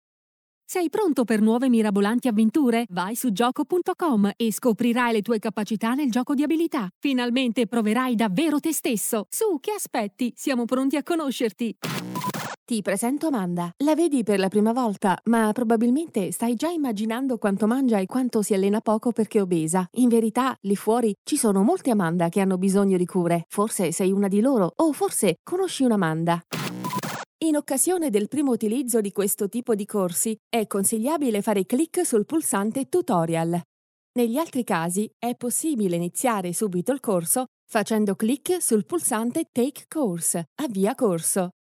Italian Voice Over
Kein Dialekt
Sprechprobe: eLearning (Muttersprache):